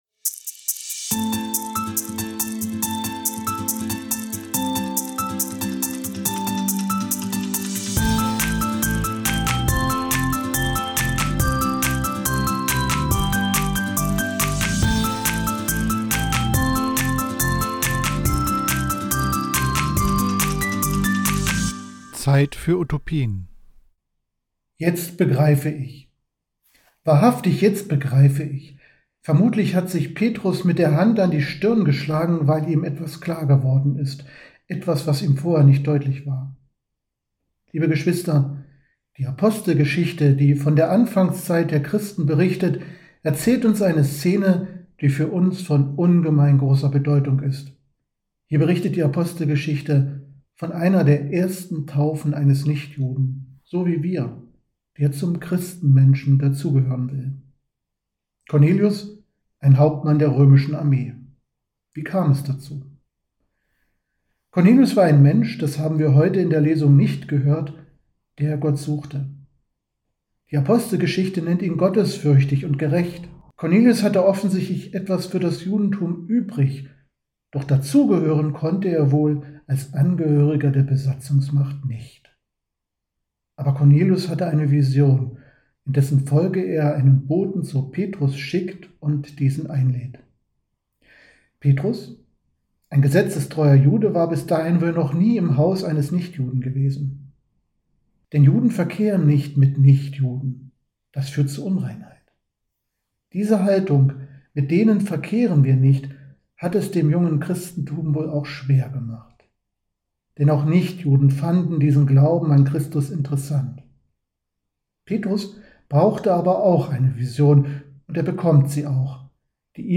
Predigt zum 6. Sonntag der Osterzeit (B) am 5.5.2024 in der Kathedrale St. Sebastian, Magdeburg